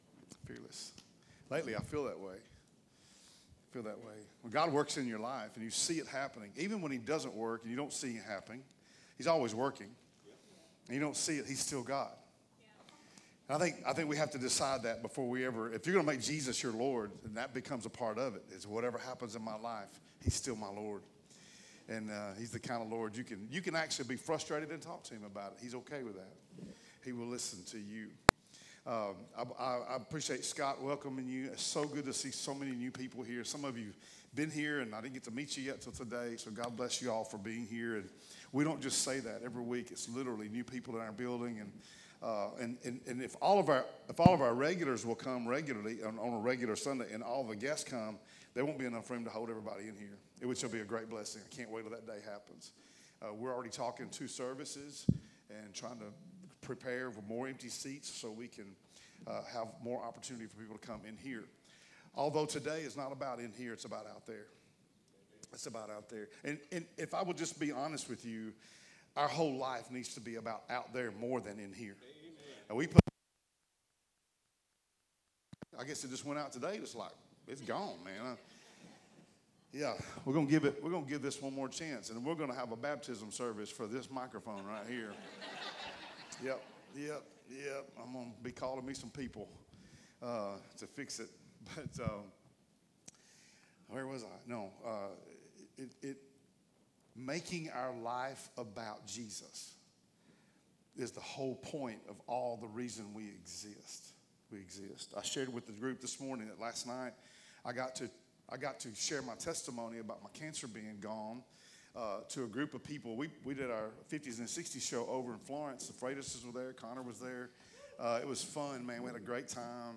Sermons | Discovery Church